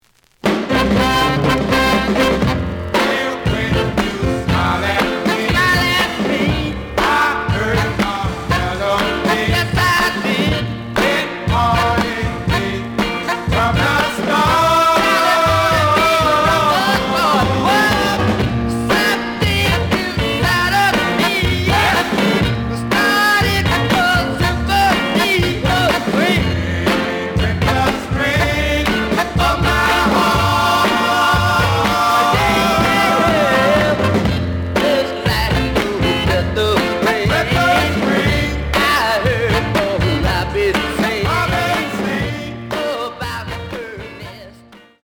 試聴は実際のレコードから録音しています。
●Genre: Rhythm And Blues / Rock 'n' Roll
盤に若干の歪み。